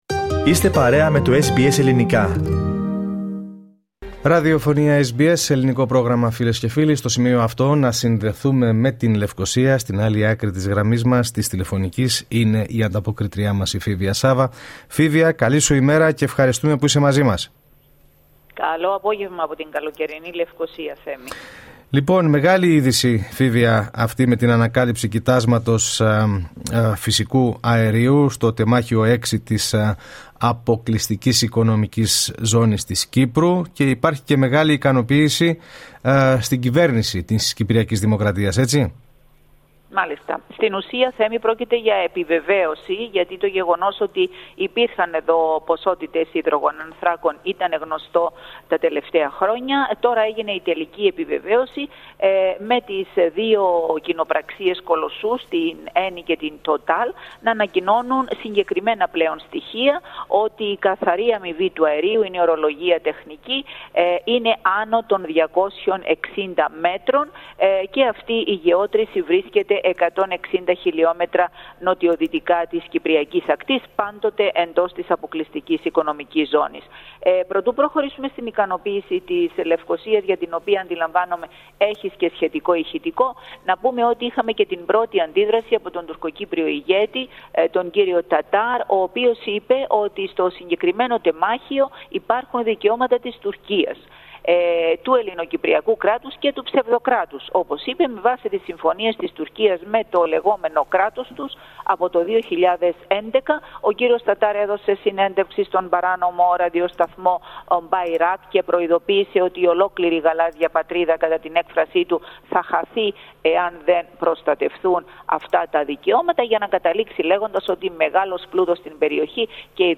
Ακούστε ολόκληρη την ανταπόκριση από την Κύπρο, πατώντας play.